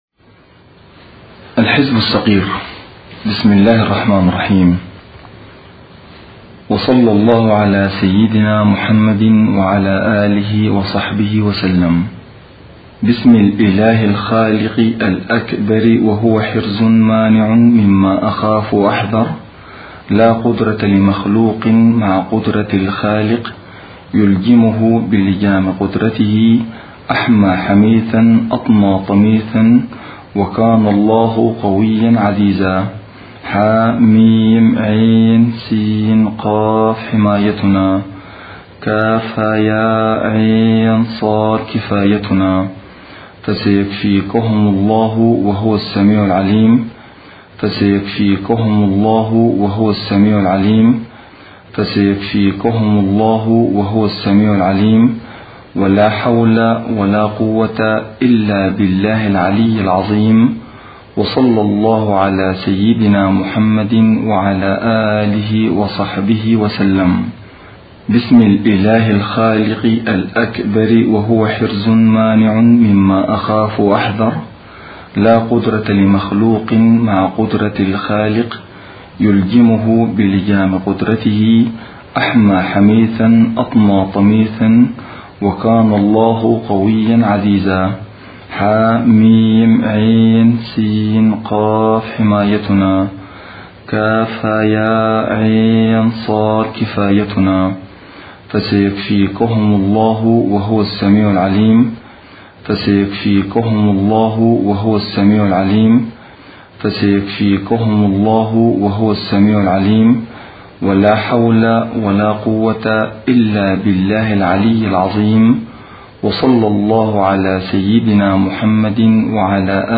قراءة (mp3)